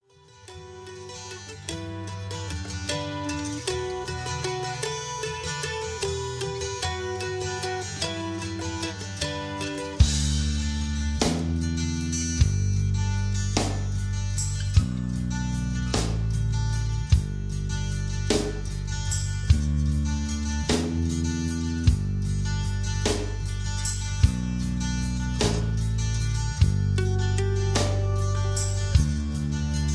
Tags: karaoke , backingtracks , soundtracks , rock